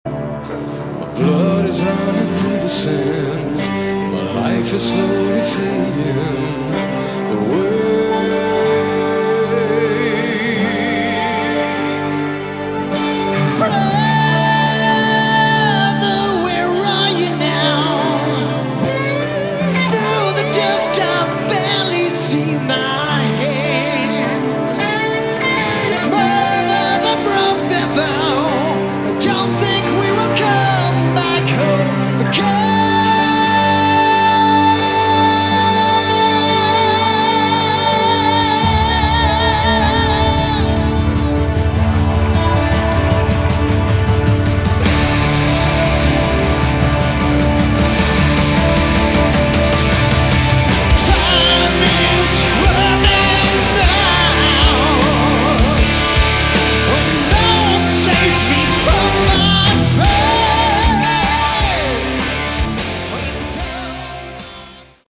Category: Melodic Rock
vocals, guitar
bass
drums